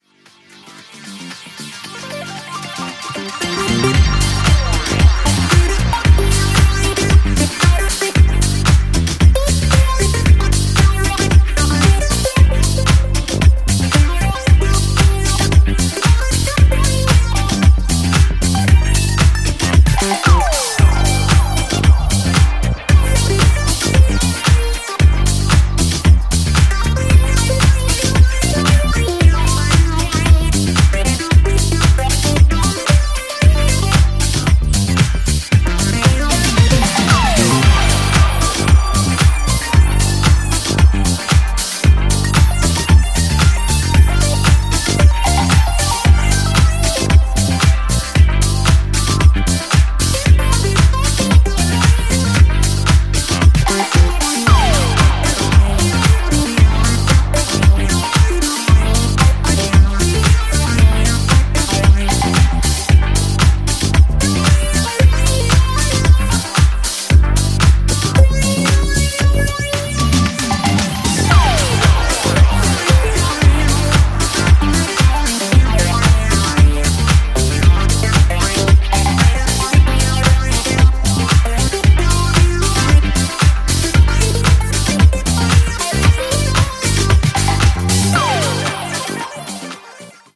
ジャンル(スタイル) DISCO / BOOGIE